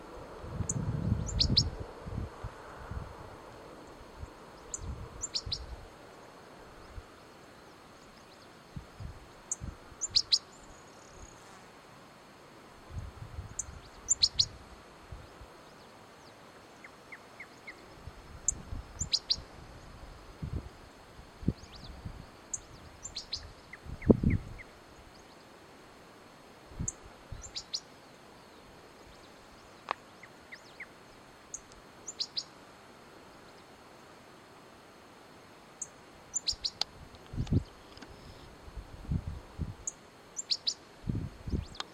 Pampa Finch (Embernagra platensis)
Location or protected area: Amaicha del Valle
Condition: Wild
Certainty: Recorded vocal